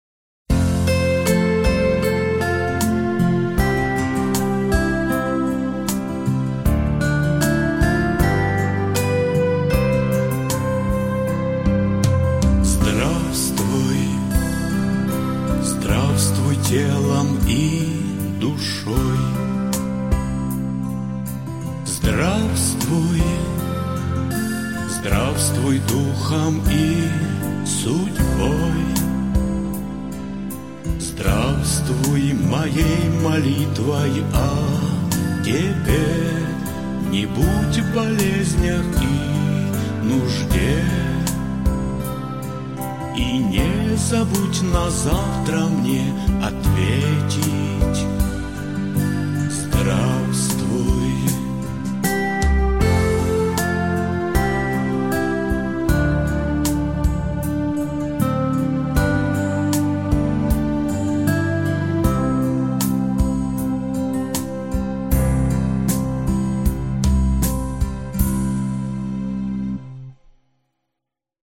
Аудиокнига Святые облака. Стихи. Песни. Молитвы | Библиотека аудиокниг